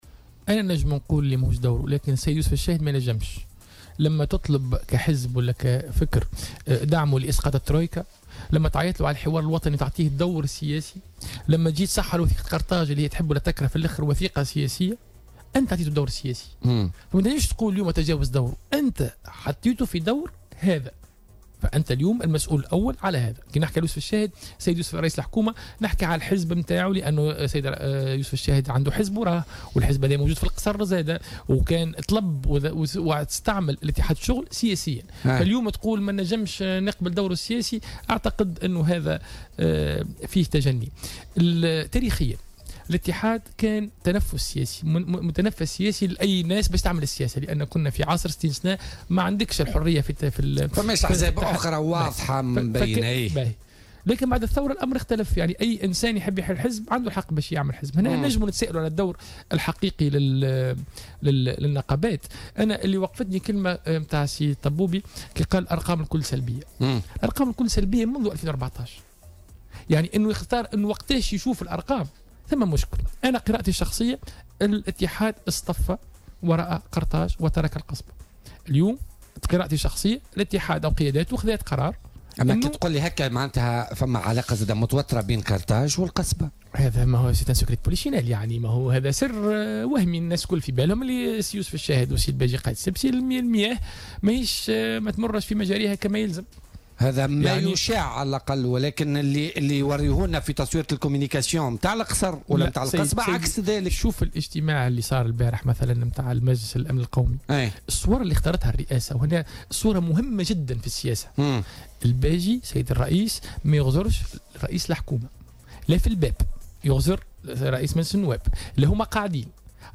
واعتبر العياري، ضيف بوليتكا اليوم الأربعاء، أنه لا يحق للشاهد أن يرفض الدور السياسي للمنظمة الشغيلة، خاصة وأن حزبه، ممثلا في رئيس الجمهورية هو من منحه هذا الدور من خلال إشراكه في وثيقة قرطاج، وفق تعبيره.